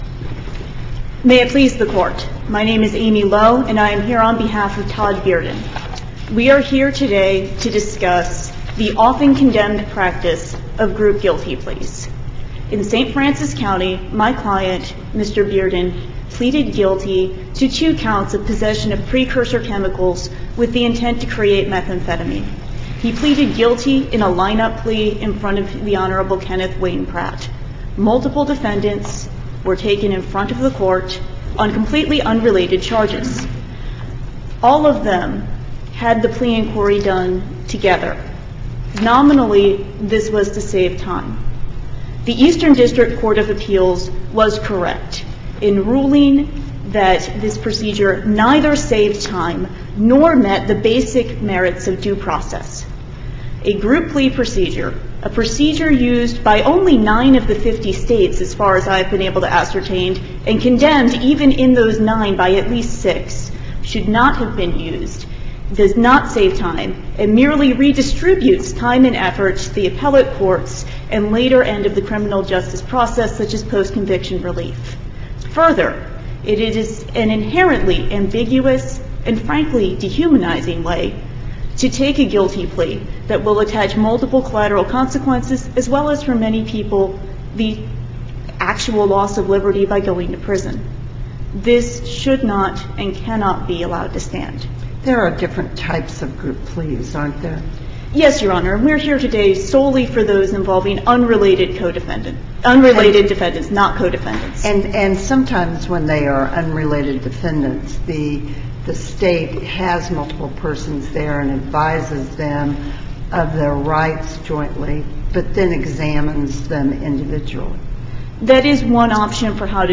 MP3 audio file of arguments in SC96032